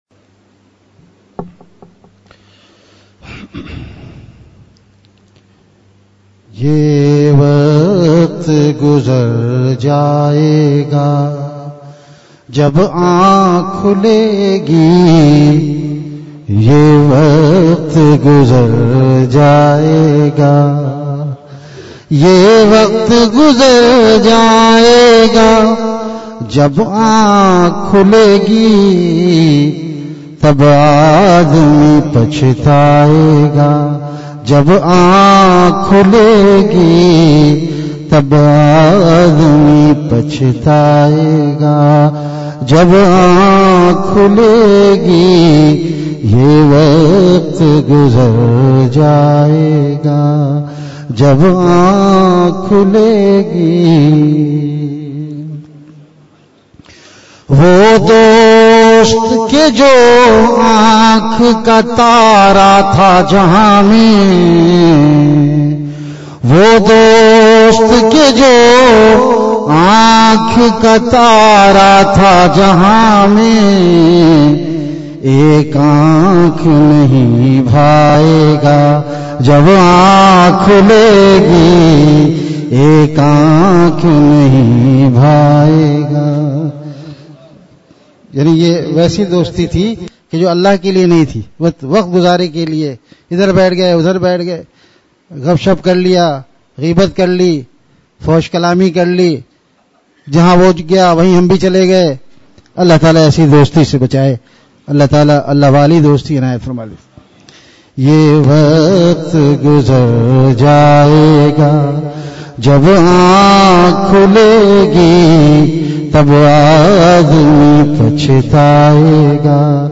Ashar Audios from Majalis